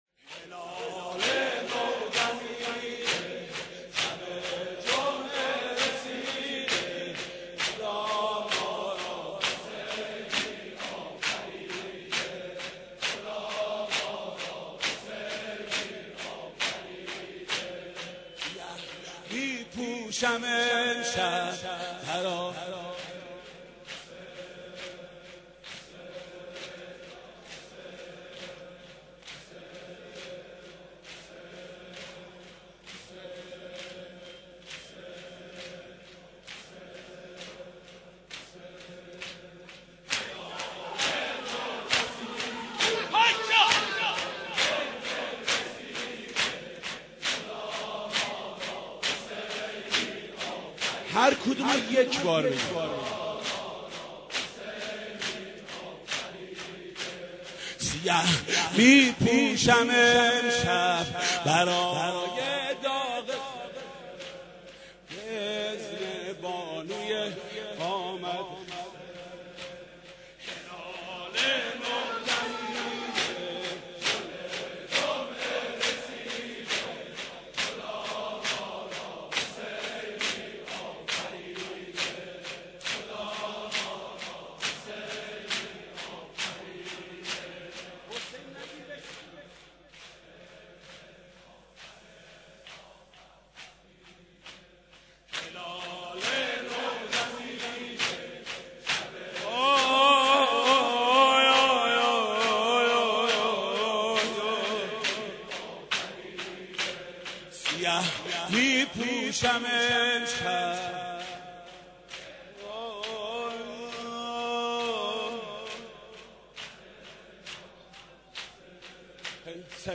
مداحی بسیار زیبا از حاج محمود کریمی+دانلود
حاج محمود کریمی/سیاه پوشان محرم سال 88